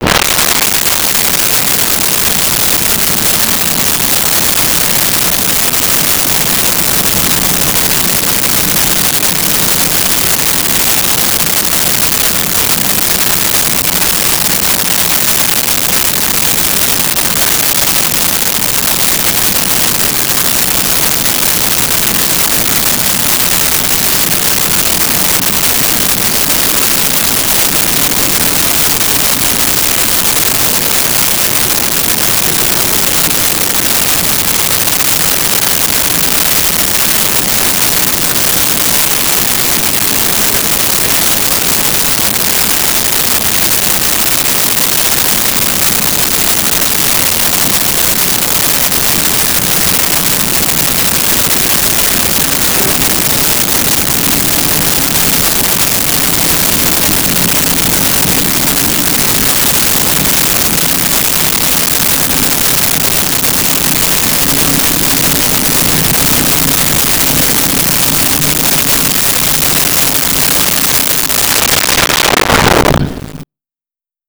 Suburban Traffic With Birds
Suburban Traffic With Birds.wav